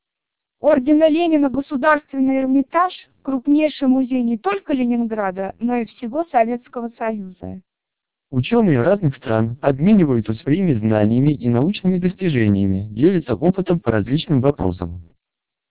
Speech Samples (WAV-files).